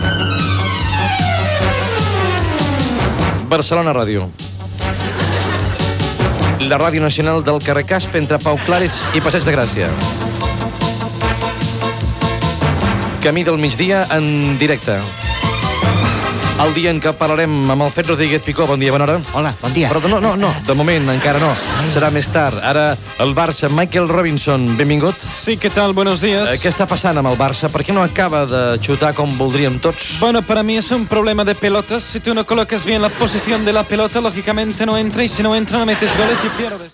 Fragment del programa, imitant l'estil de presentació de Josep Cuní.
Entreteniment